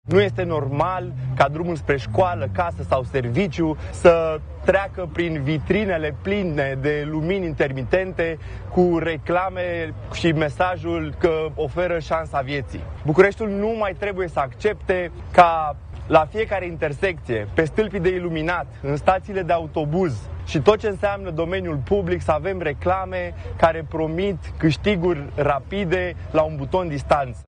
Consilierul general Dragoș Radu – USR – este și inițiatorul unui proiect care interzice reclamele la jocuri pe domeniul public, proiect care așteaptă votul în Consiliul General:
Mai mulți membri USR, într-o conferință de presă ținută într-un cartier de blocuri, au ținut în mâini pancarde cu mesajele: „Fără păcănele lângă școli” și „Jocurile de noroc distrug vieți”.